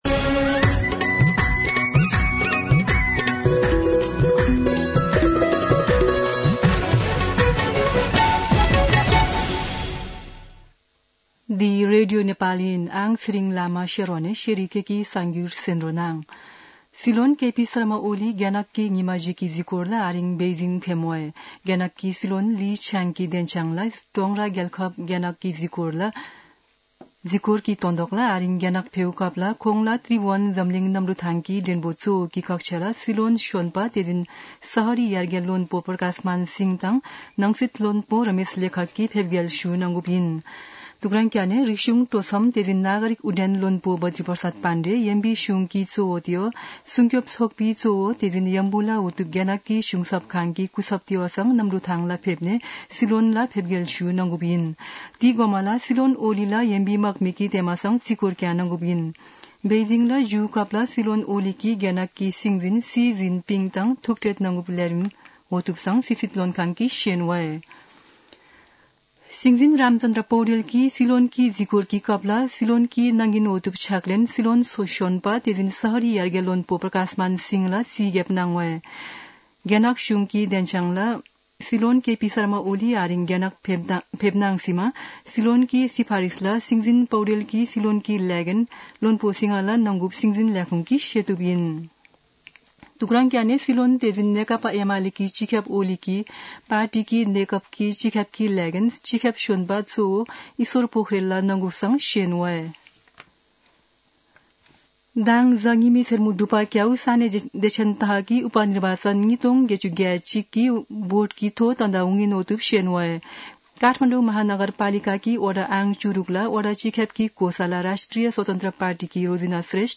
शेर्पा भाषाको समाचार : १८ मंसिर , २०८१
sherpa-news-.mp3